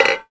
grenade_hit3.wav